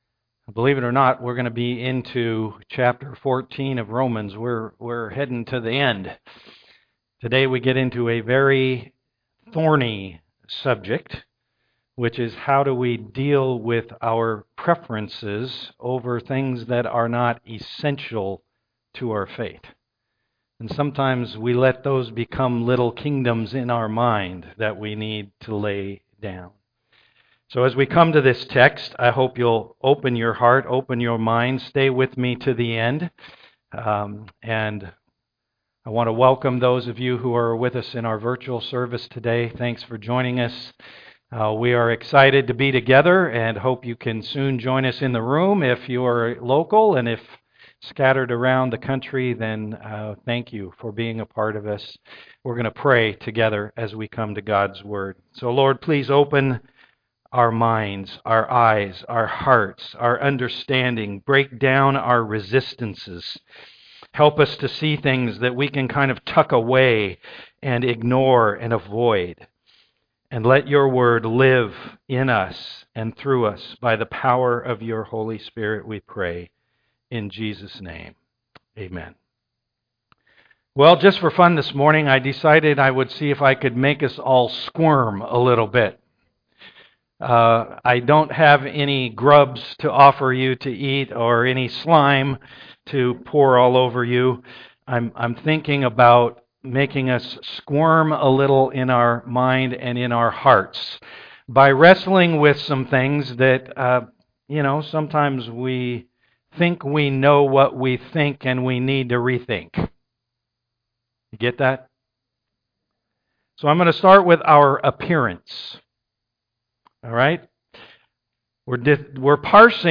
Romans 14:1-12 Service Type: am worship It's so easy to judge